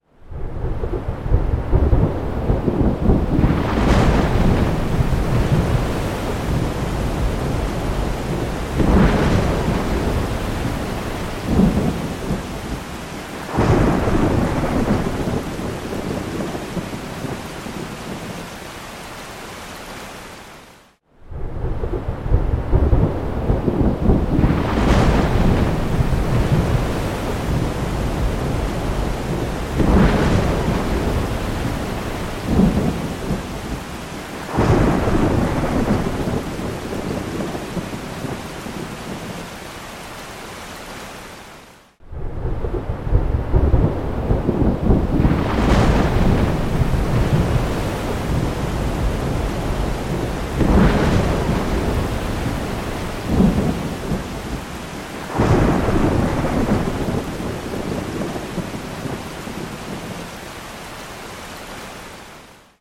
Âm thanh Mưa Gió và Sấm Sét
Created by: tiếng mưa gió sấm chớp
Thể loại: Tiếng thiên nhiên
am-thanh-mua-gio-va-sam-set-www_tiengdong_com.mp3